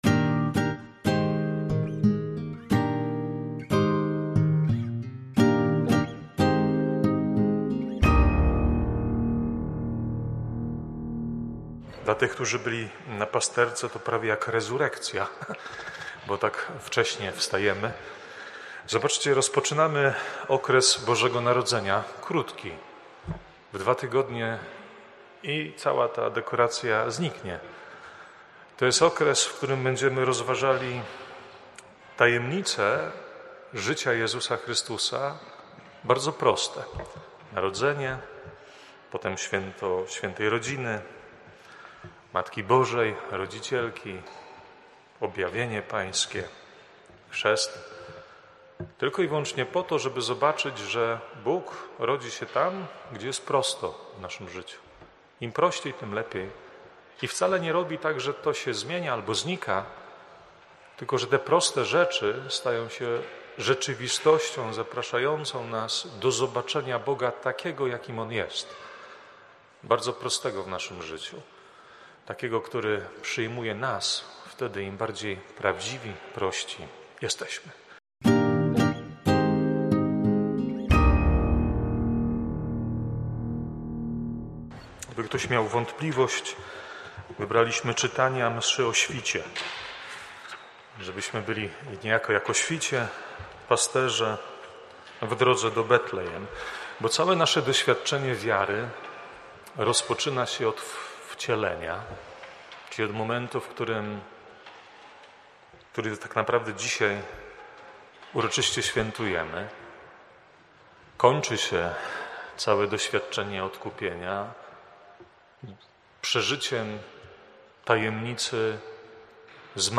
wprowadzenie do Liturgii oraz kazanie: